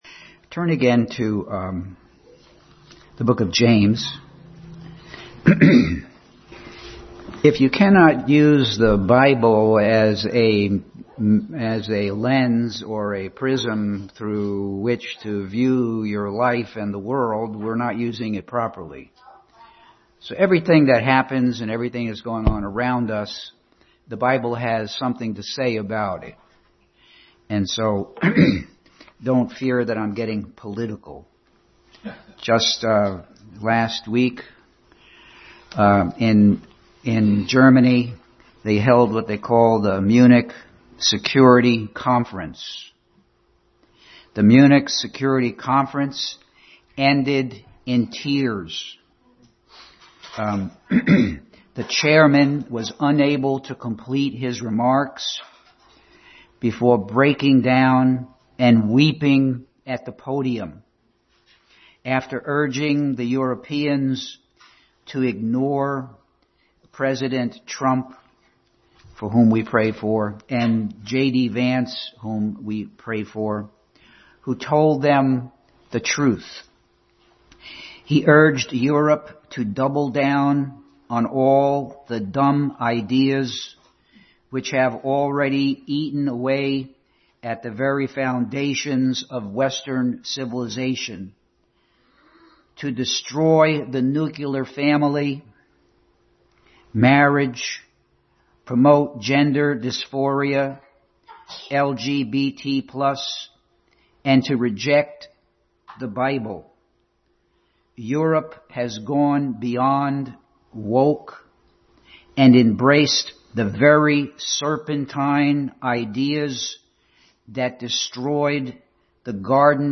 Passage: James 1:22, Ephesians 2:8-10, 2 Timothy 3:16-17, Luke 2:46, Romans 6:13, Acts 1:1 Service Type: Family Bible Hour